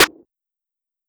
Snare (Everything We Need).wav